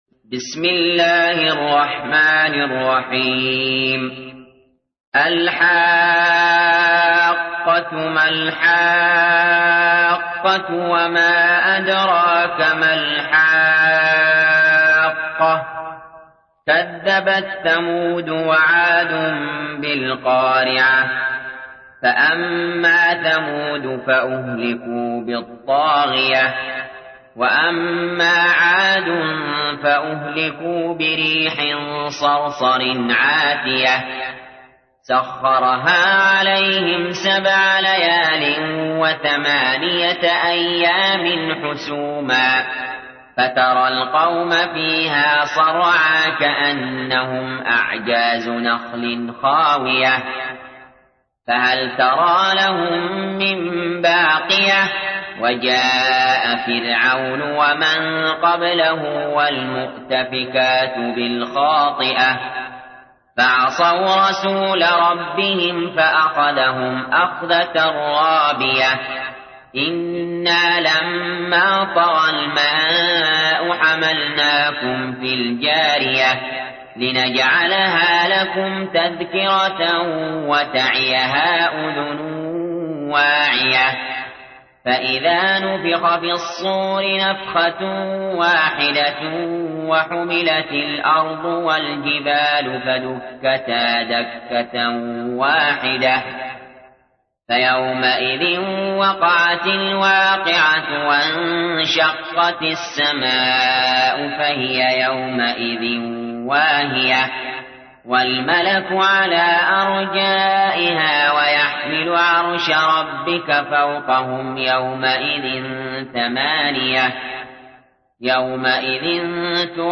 تحميل : 69. سورة الحاقة / القارئ علي جابر / القرآن الكريم / موقع يا حسين